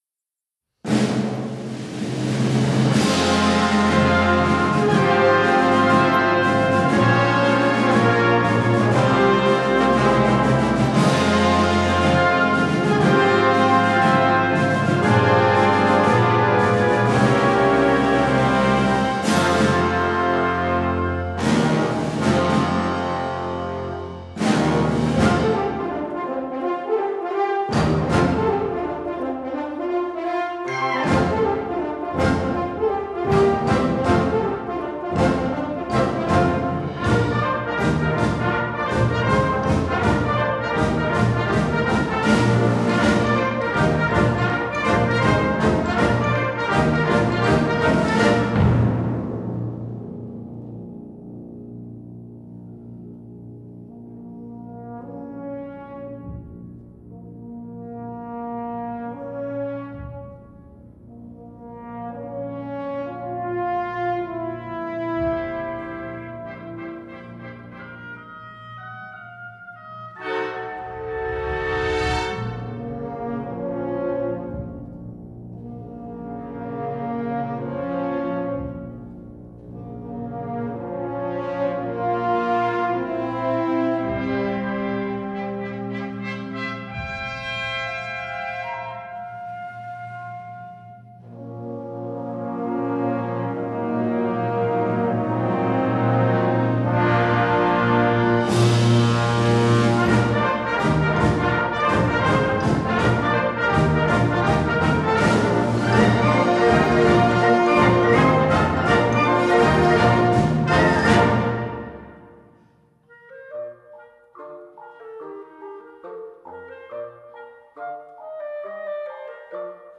Konzertwerk für Blasorchester
Besetzung: Blasorchester